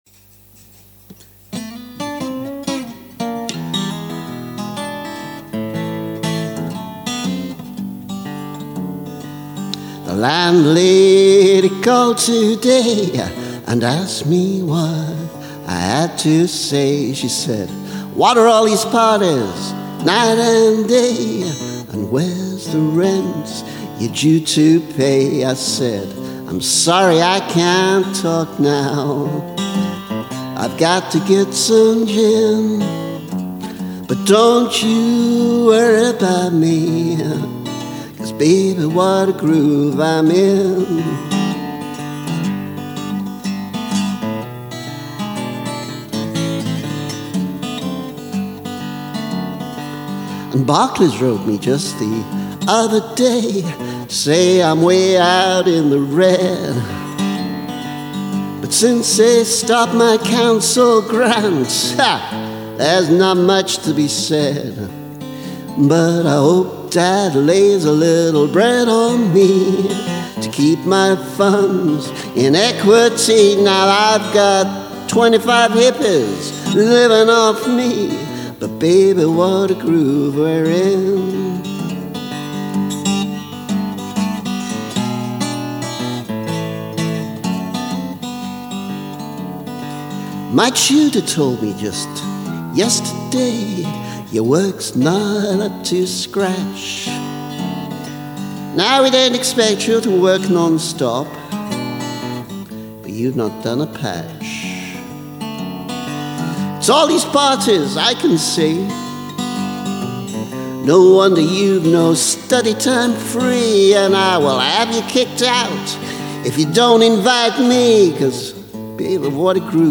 Remastered: